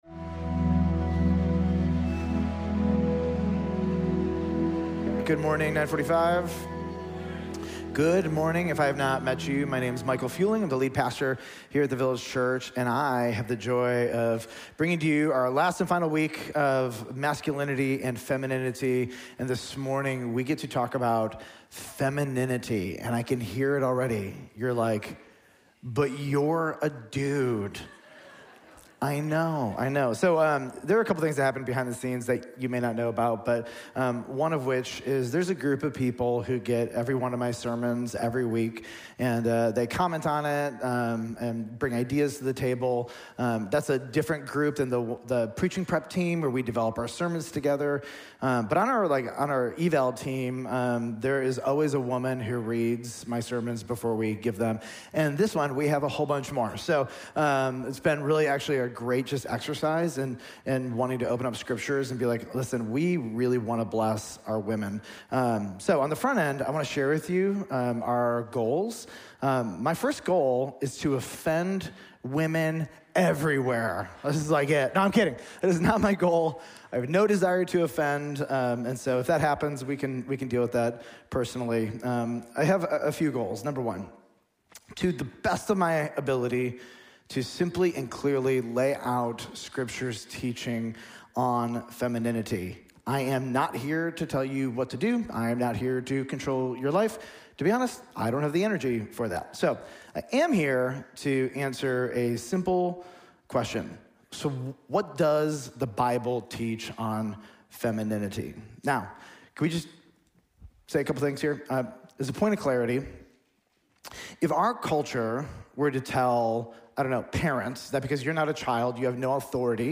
Sermons Masculinity and Femininity Pt. 3: Biblical Femininity